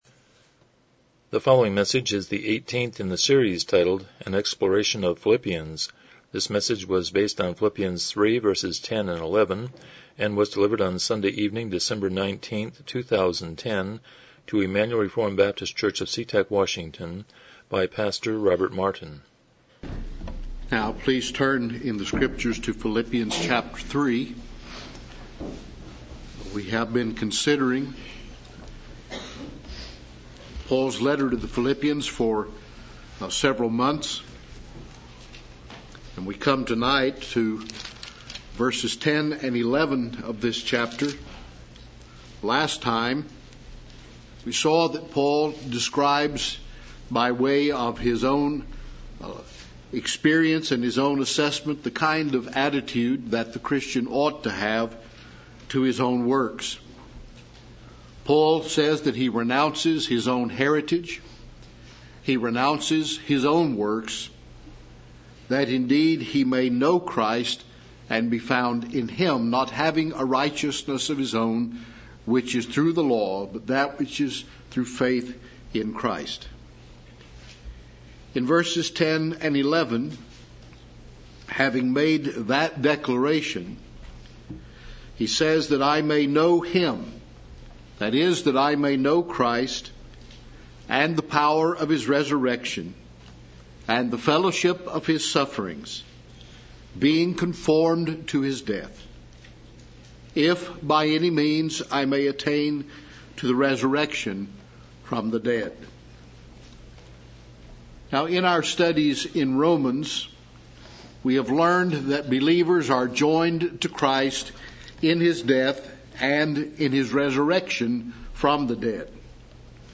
Philippians 3:10-11 Service Type: Evening Worship « 127 Romans 10:5-13